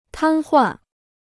瘫痪 (tān huàn) Dictionnaire chinois gratuit